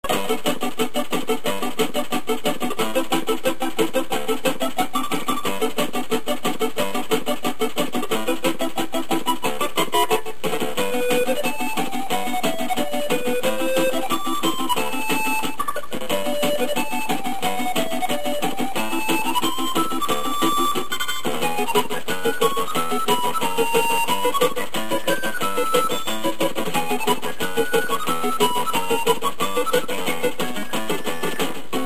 They are now in mono sound at 32khz, 64kbps in .mp3 format.